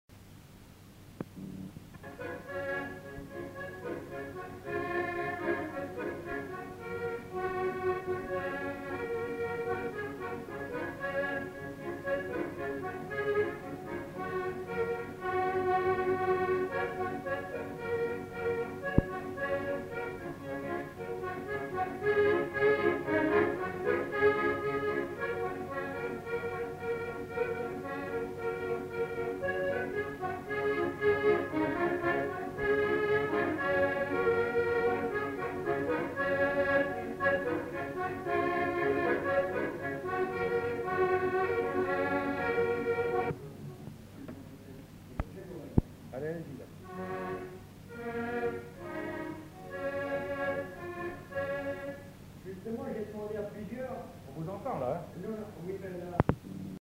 Instrumental. Accordéon diatonique
Lieu : Monclar d'Agenais
Genre : morceau instrumental
Instrument de musique : accordéon diatonique
Notes consultables : Polka ou marche ?
Ecouter-voir : archives sonores en ligne